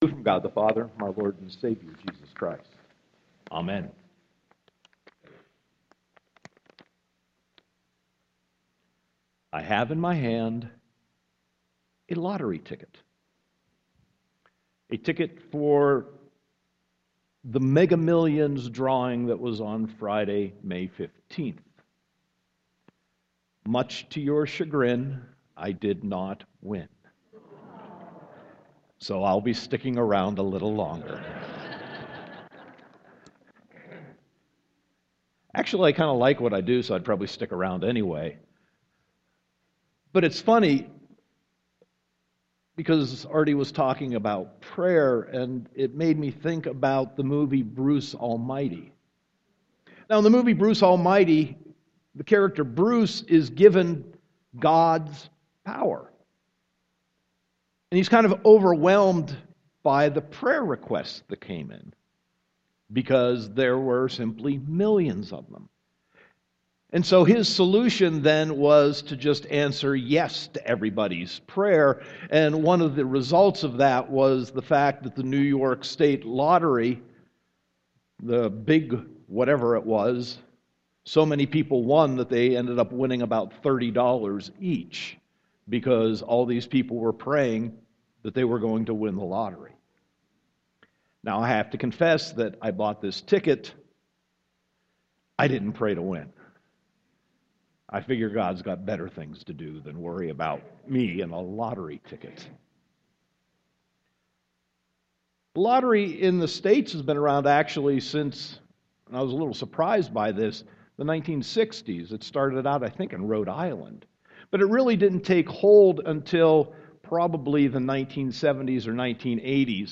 Sermon 5.17.2015